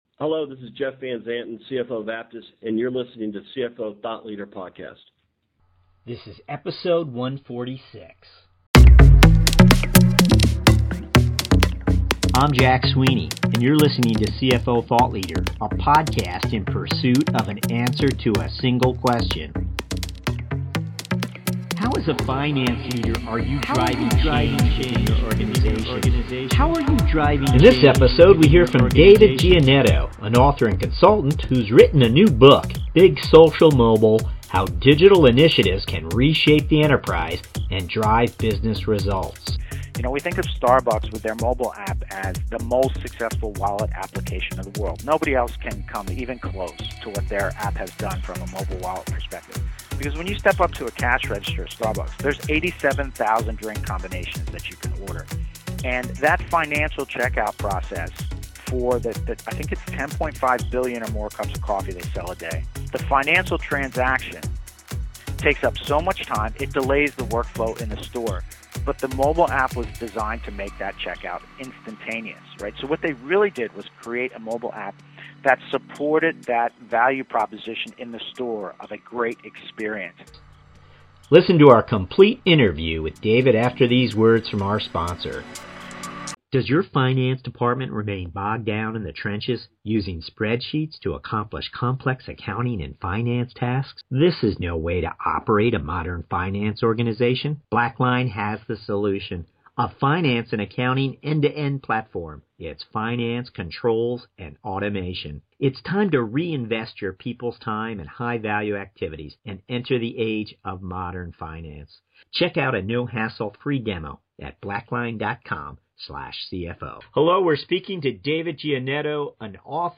Interview – CFO Thought Leader